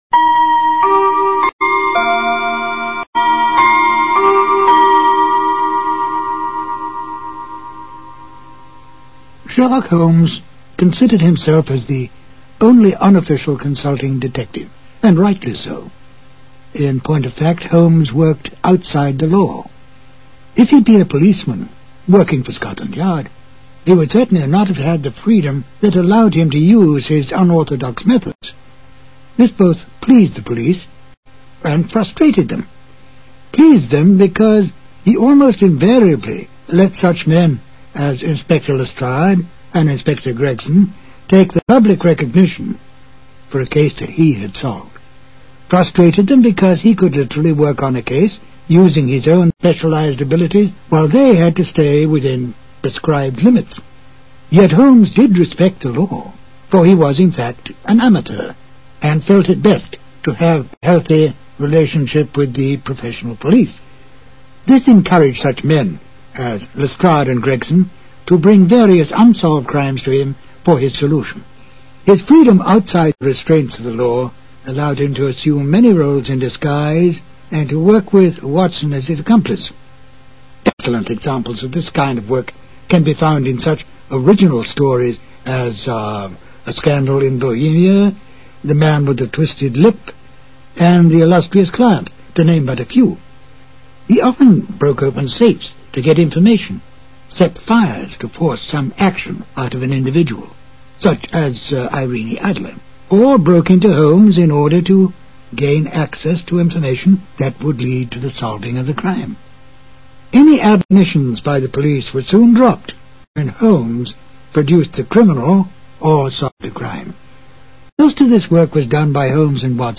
Radio Show Drama with Sherlock Holmes - The Waltz Of Death 1946